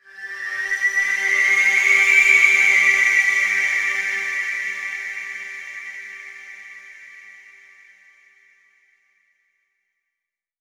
VEC3 FX Athmosphere 15.wav